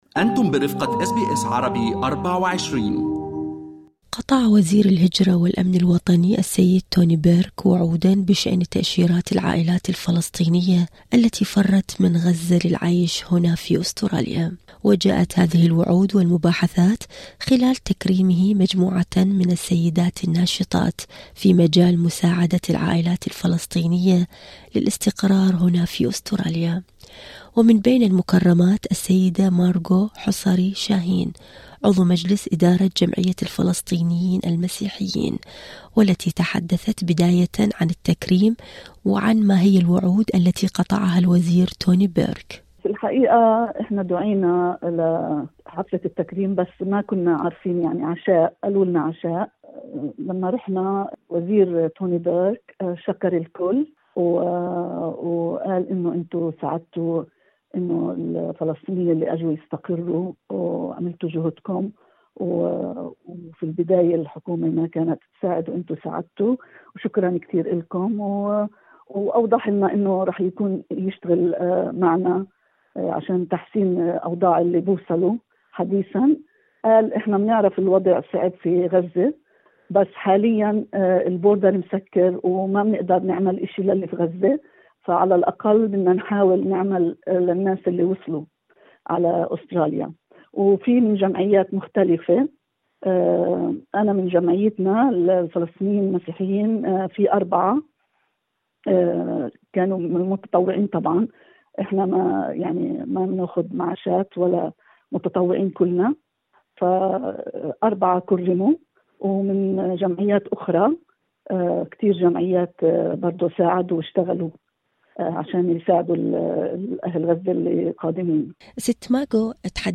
المزيد عن حديث ووعود وزير الهجرة توني بيرك في المقابلة الصوتية اعلاه هل أعجبكم المقال؟